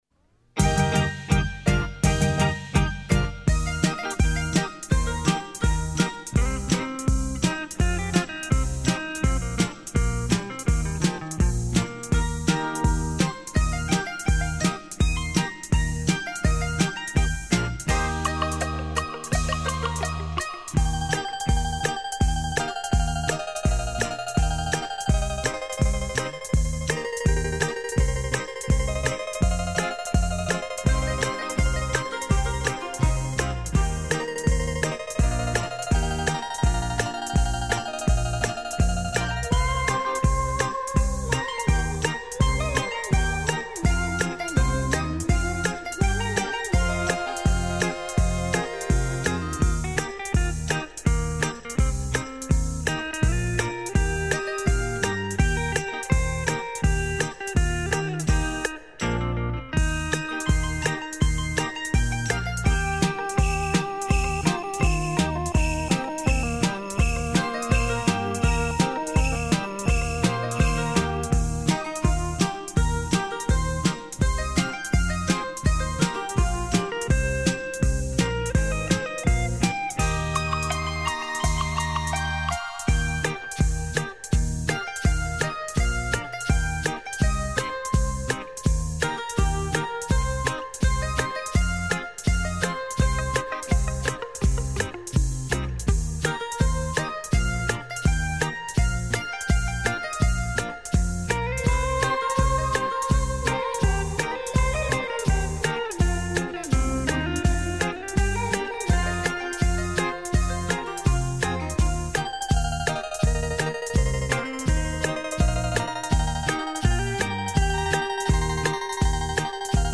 行云流水的音，清澈透明的琴声。
音色通透鲜明，音像玲珑浮现，发烧友不能不买。